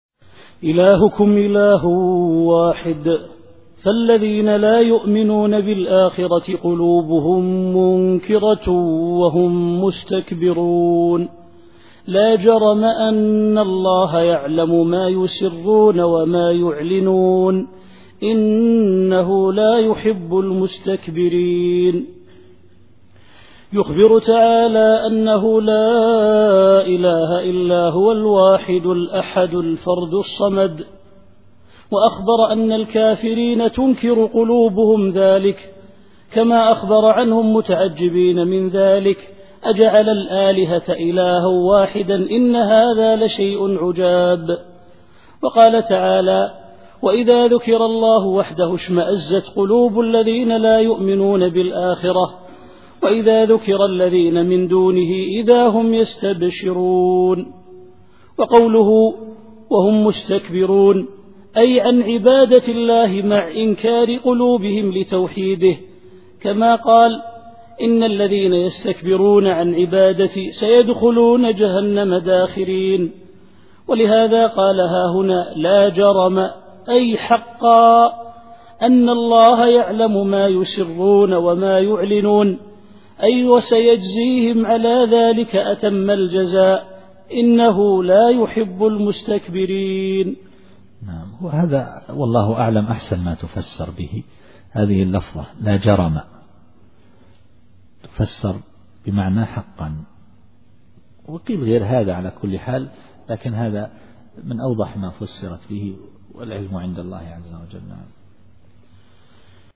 التفسير الصوتي [النحل / 23]